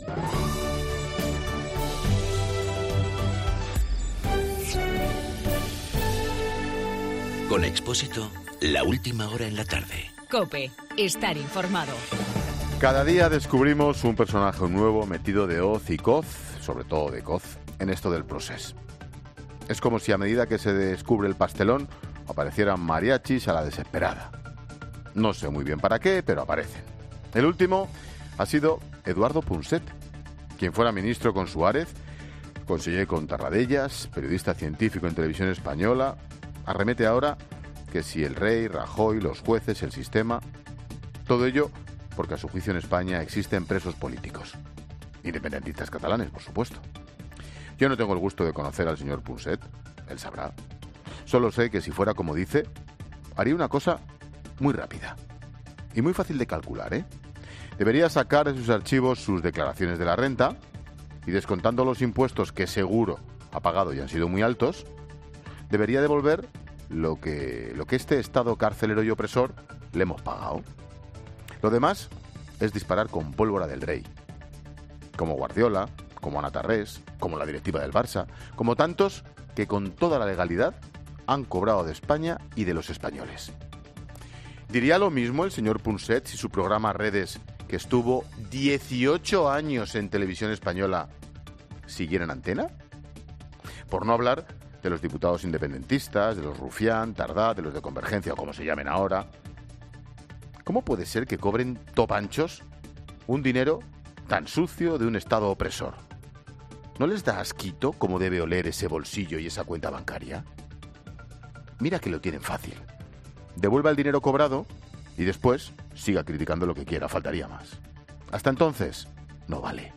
AUDIO: El comentario de Ángel Expósito por las críticas a España del divulgador científico Eduardo Punset.
Monólogo de Expósito